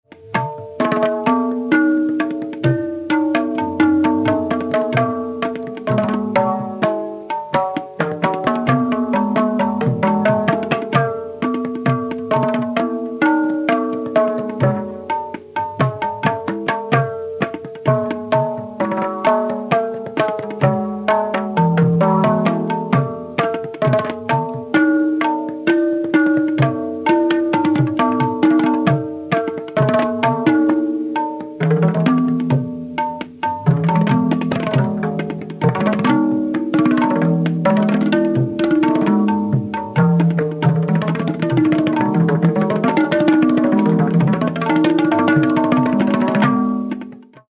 S-G-M-P-N-S
Aroha: SGMPNS
Avroh: SNPMGS
Pakad: variable, e.g. NSGPM; GMGPG
• Tanpura: Sa–Pa